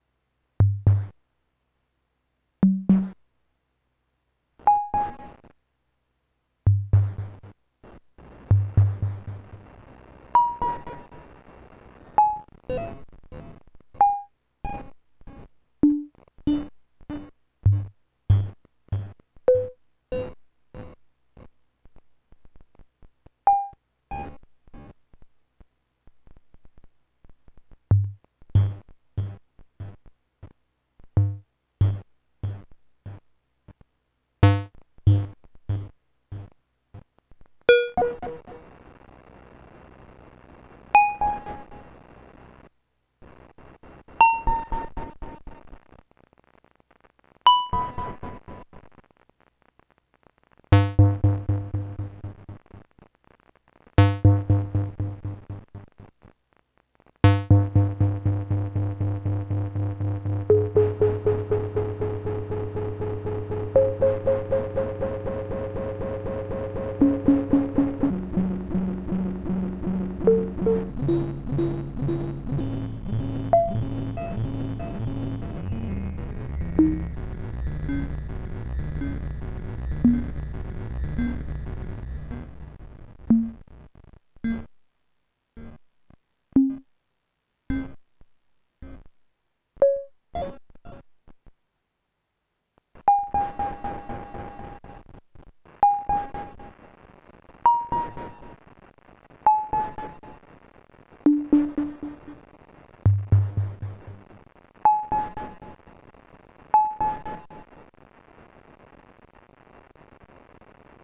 Finally I got something barely working when trying to make a delay with Arduino nano:
Delay with 2 bit bitrate and samplerate of 16kHz divided by some integer, or delay times of about 270 ms to 1.2 seconds, when output doesn’t really sound a lot about what went in.
2bit-delay.mp3